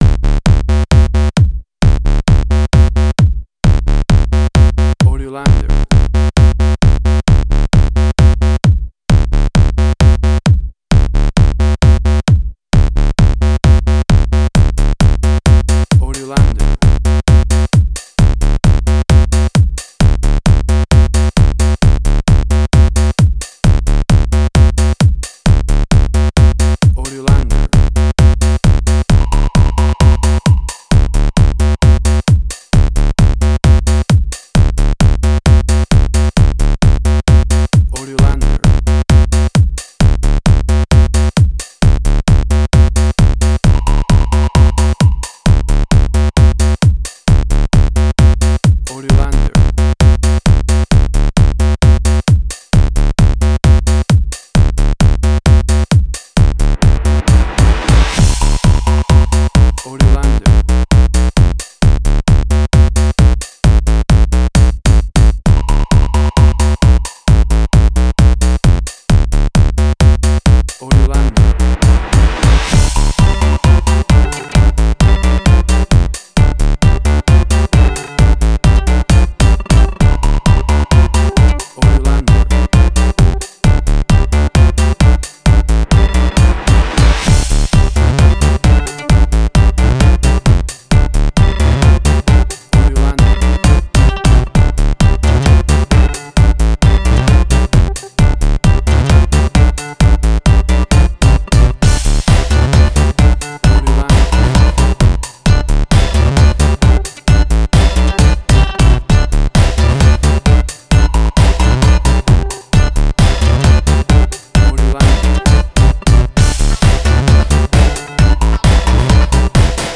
House.
WAV Sample Rate: 16-Bit stereo, 44.1 kHz
Tempo (BPM): 130